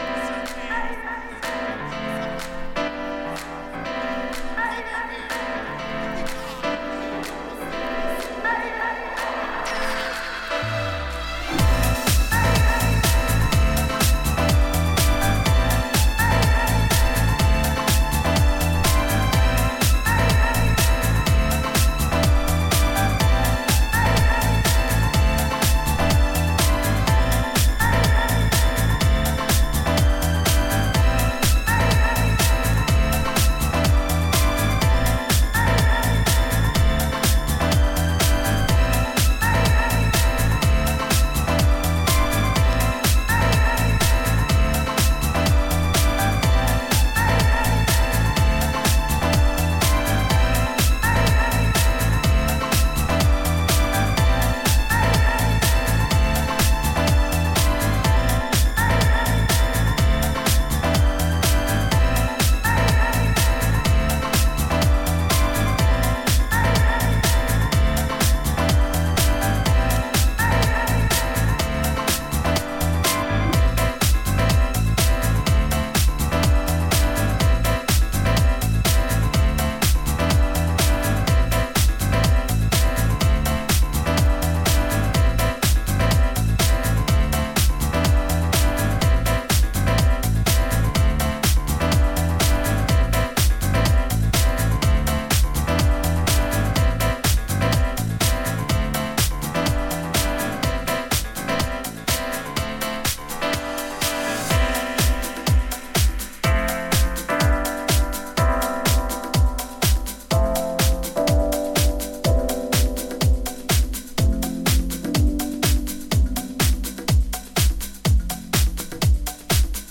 軽快で色艶のあるピアノ・リフやソウルフルなヴォーカル・サンプルなどを用いながらモダンでウォームなハウスを展開していった、